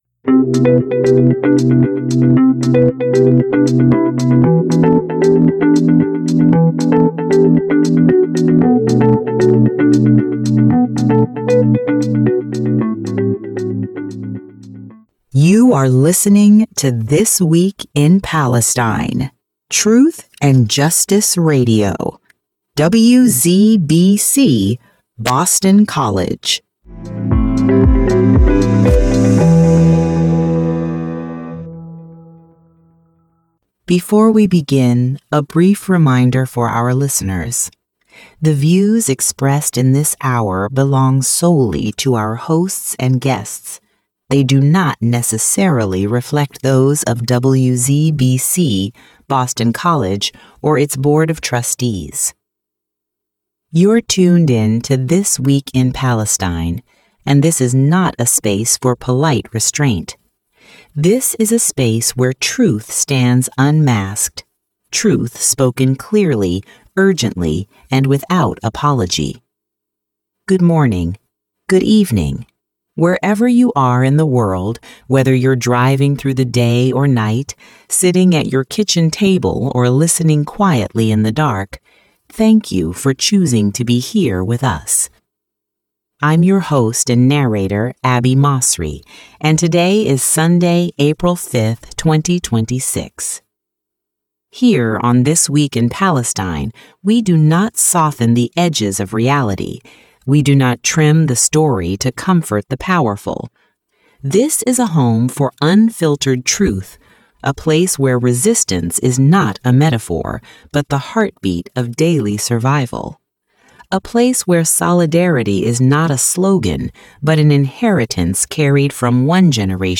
TWIP-260405 Subtitle: TWIP-260405 When Leadership Falters, Ordinary People Pay the Price. Program Type: Weekly Program Speakers